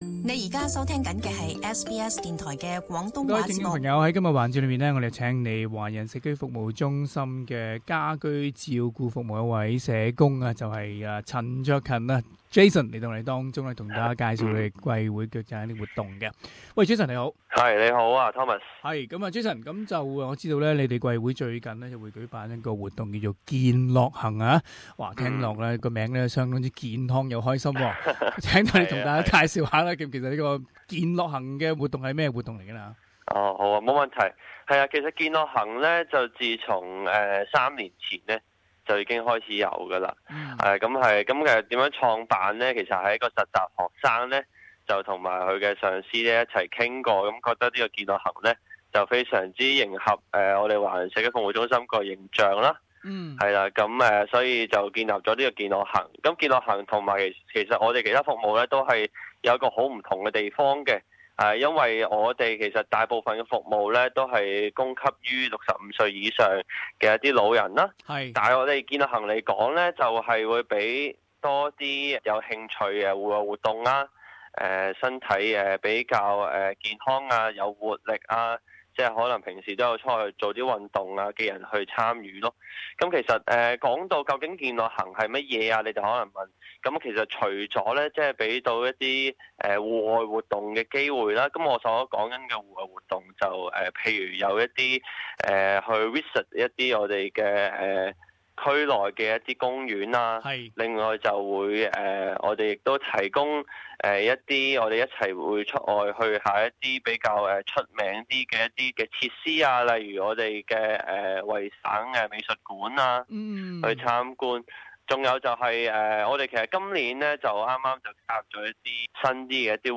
【社團專訪】華人社區服務中心推出健樂行活動詳情